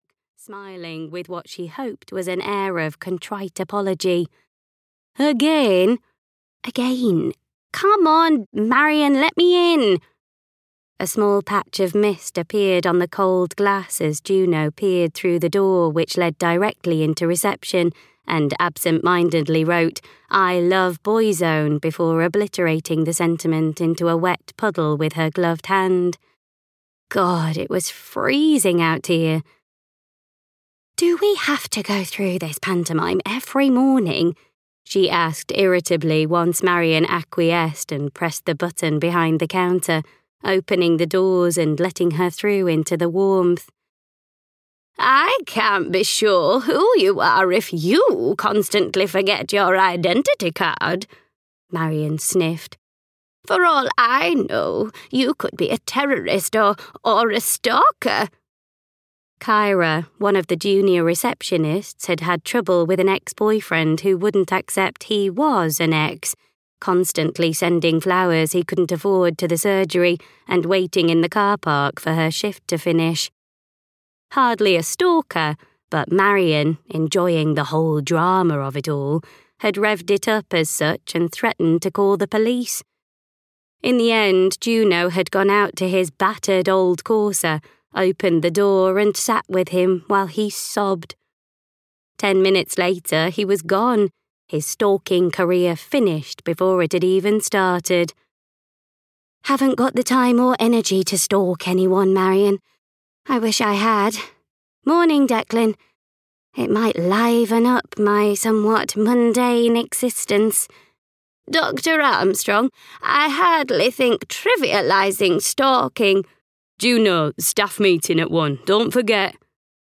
Sing Me a Secret (EN) audiokniha
Ukázka z knihy